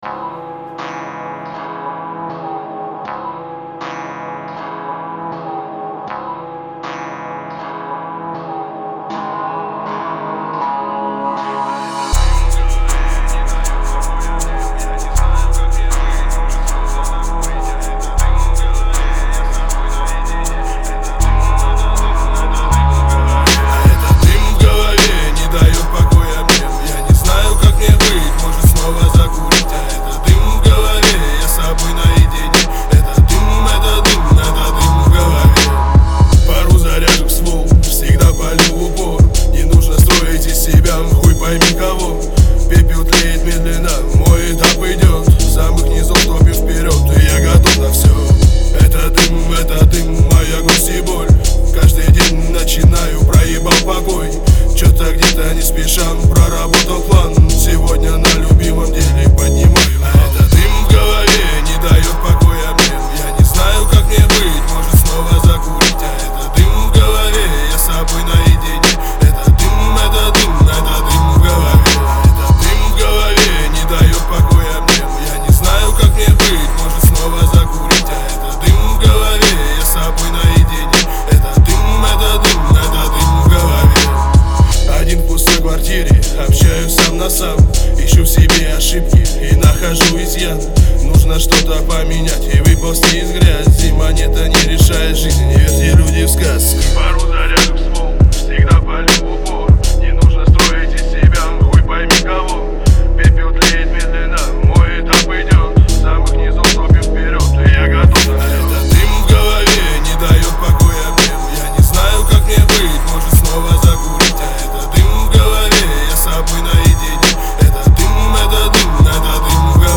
это атмосферный трек в жанре альтернативного рока.